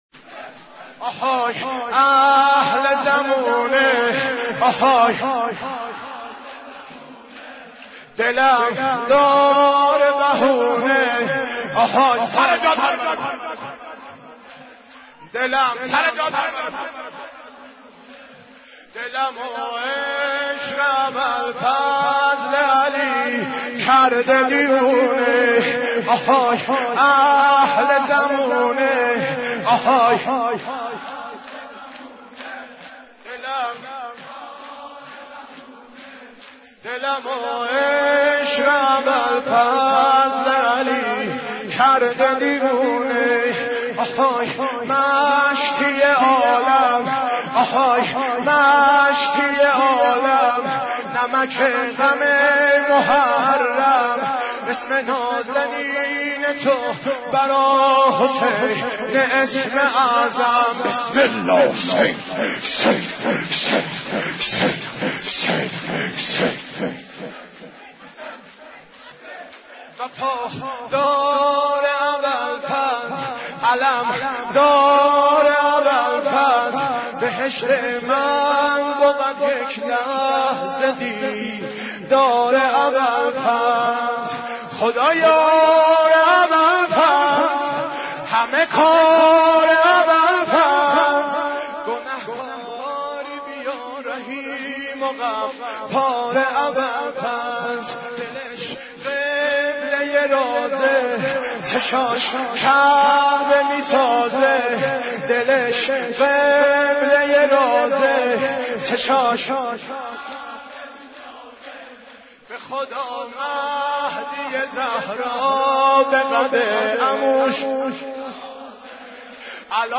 حضرت عباس ع ـ شور 15